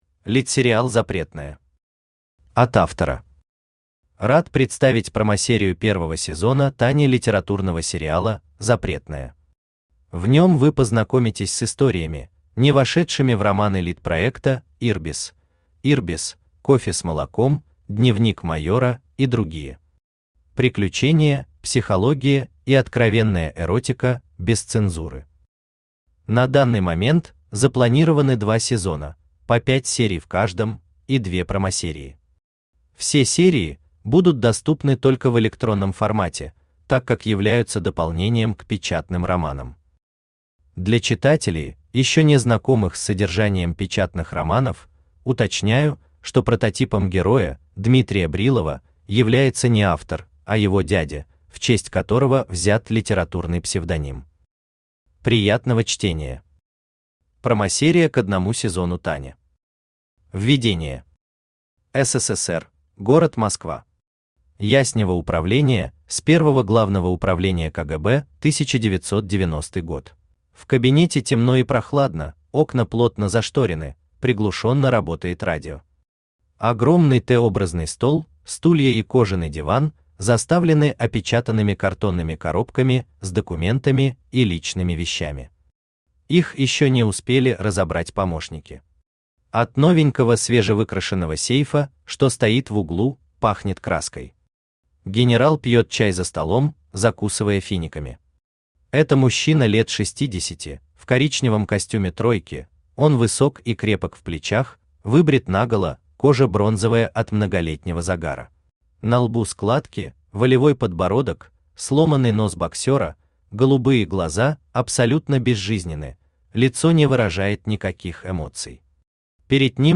Аудиокнига Запретное: Промо | Библиотека аудиокниг
Aудиокнига Запретное: Промо Автор Дмитрий Брилов Читает аудиокнигу Авточтец ЛитРес.